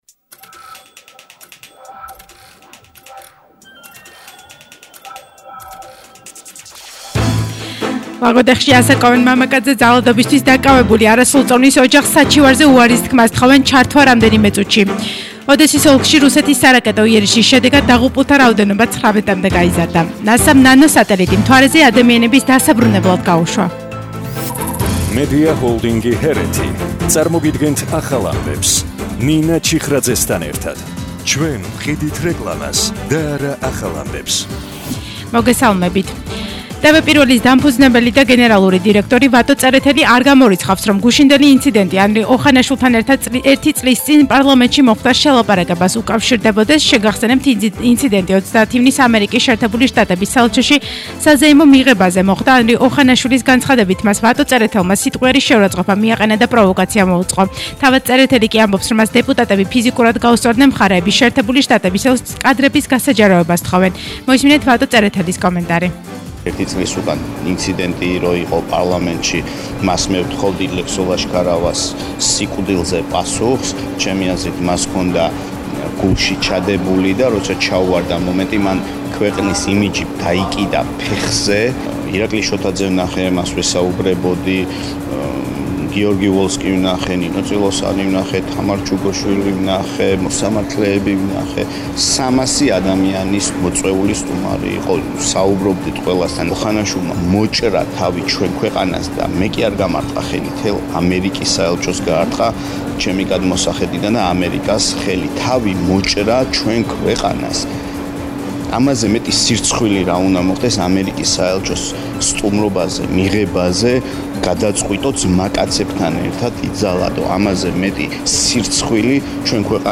ახალი ამბები 16:00 საათზე – 01/07/22